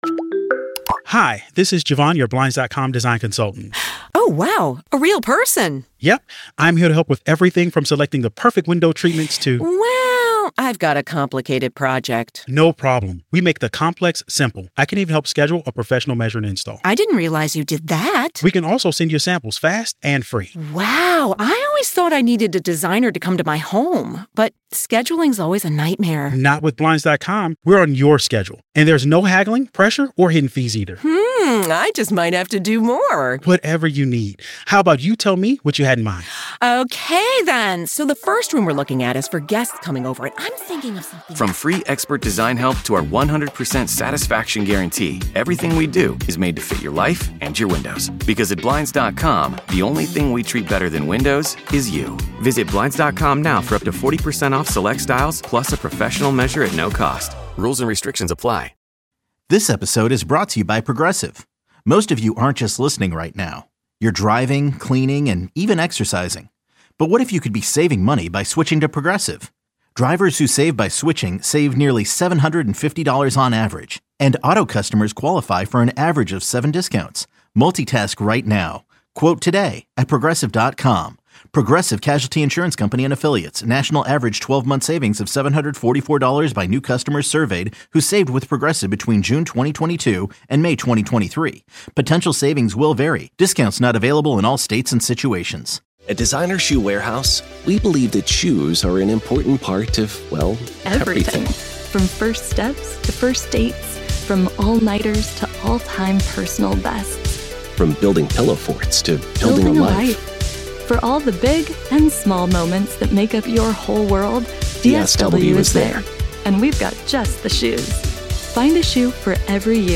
A live post-show that airs twice per week (Tuesdays and Thursdays)